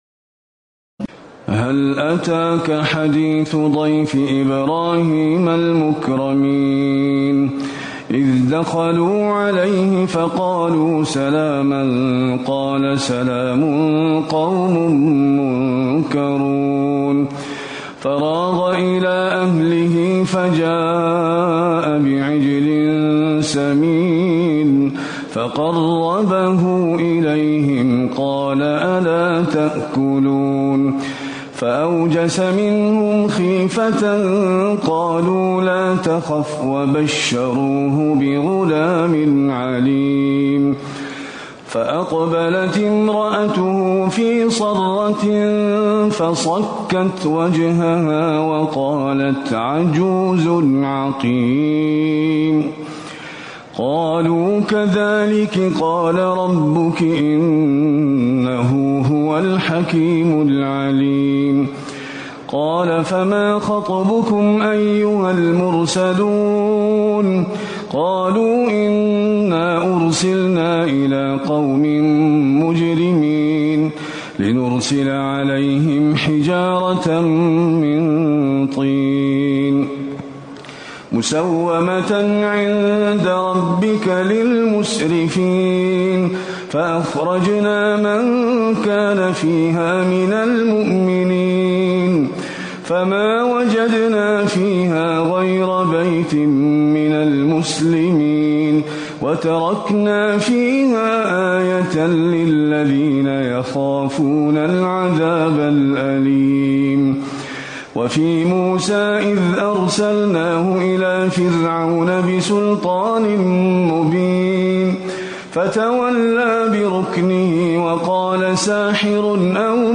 تراويح ليلة 26 رمضان 1437هـ من سور الذاريات (24-60) و الطور و النجم و القمر Taraweeh 26 st night Ramadan 1437H from Surah Adh-Dhaariyat and At-Tur and An-Najm and Al-Qamar > تراويح الحرم النبوي عام 1437 🕌 > التراويح - تلاوات الحرمين